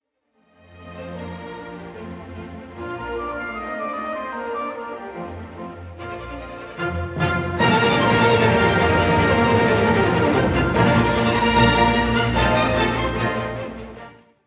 Allegro ma non troppo - mm. 15 to 25 — The Orchestral Bassoon
Beethoven+4+(Ex+1)+-+London+-+Monteux.mp3